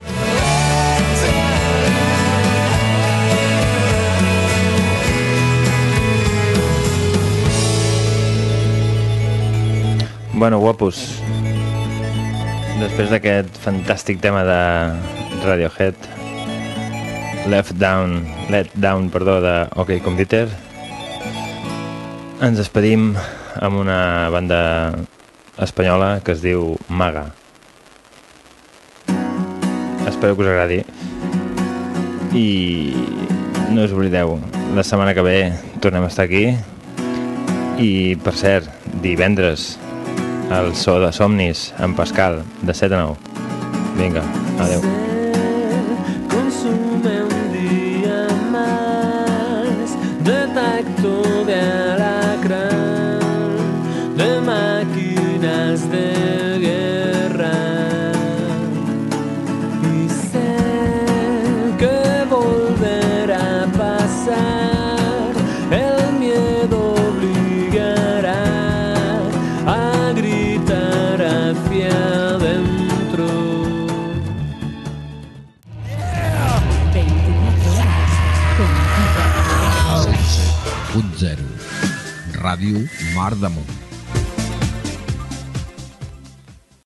Tema musical, comiat del programa, tema musical i indicatiu de l'emissora
Musical